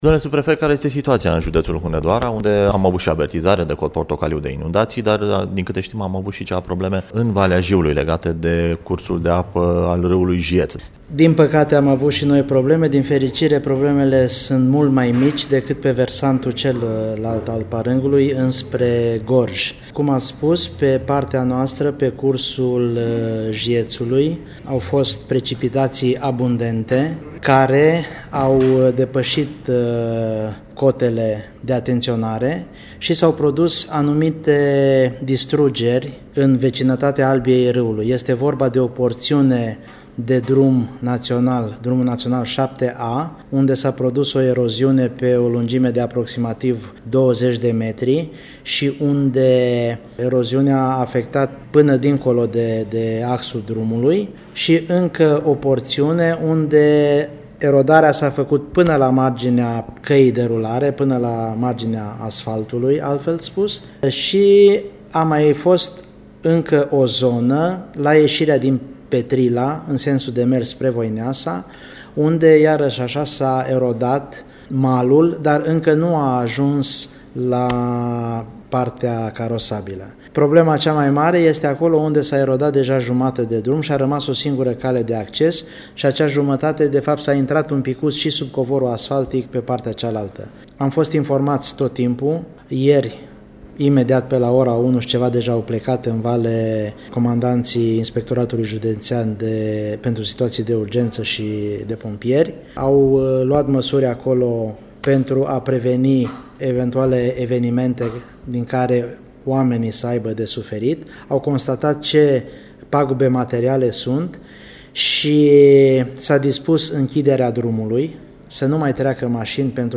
Un interviu acordat de subprefectul judeţului Hunedoara, Ioan Gabriel Samoilescu pentru Radio Timişoara.